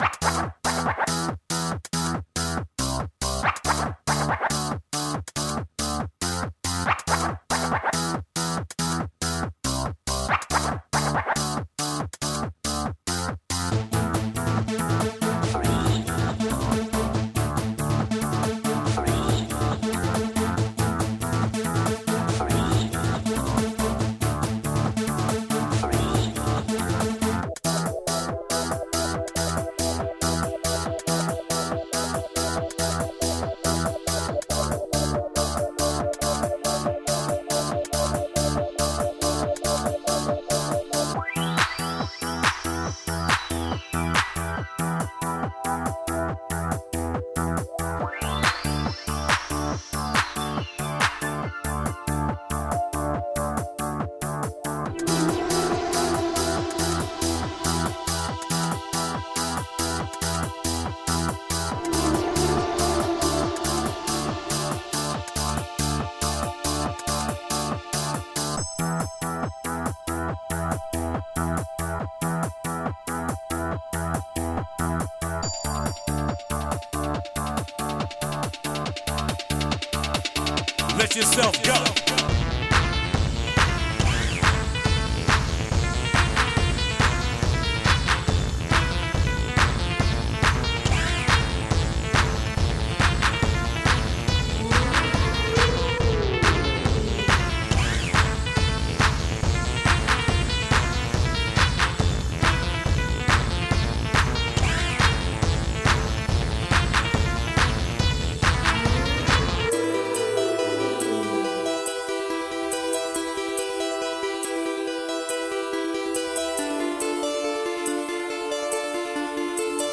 ダンス音楽系のノリですから、そういうのが好きではない人は避けた方が良いと思います。
gungで，Confuserという、要はでたらめな音を収めたパーツを発見したので、これを、ここぞという場所で使ってみたもの。